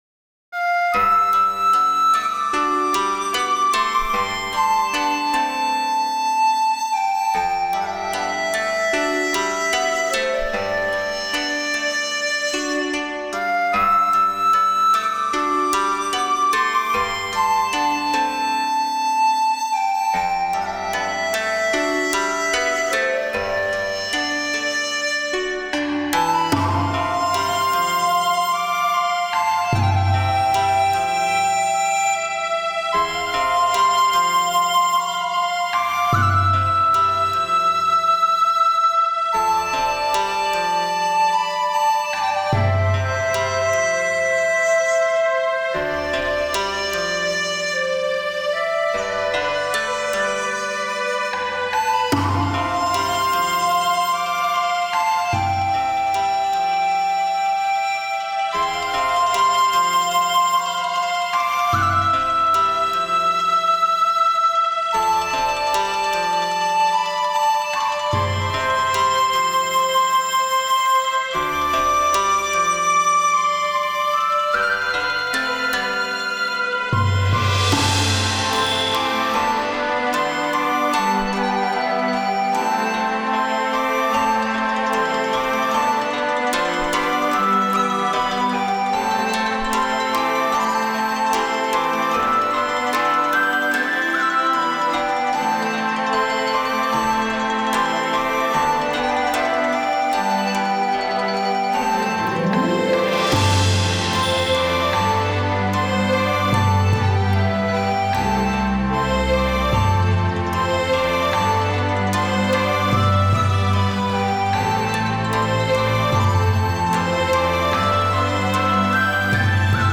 ジャンル 和風 バイオリン 打楽器 琴 尺八 癒し 日常